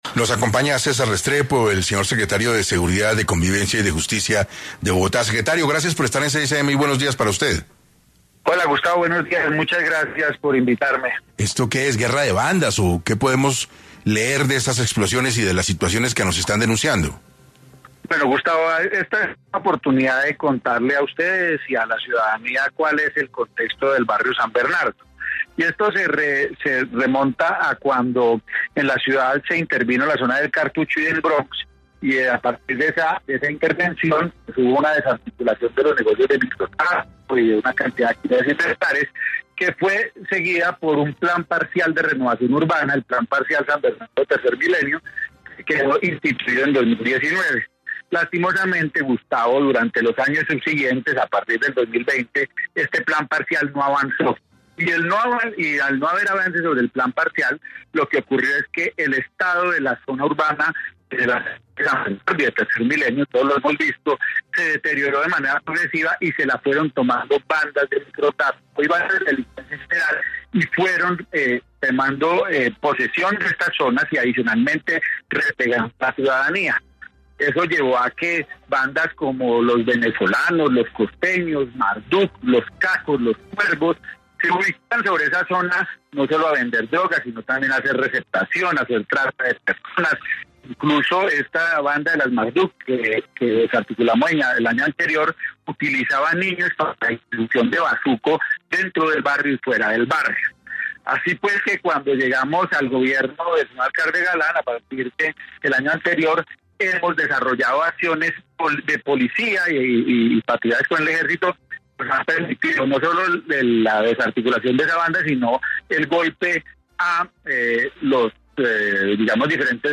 En 6AM estuvo César Restrepo, secretario de Seguridad, Convivencia y Justicia de Bogotá, quien se refirió a la explosión presentada en el barrio San Bernardo en Bogotá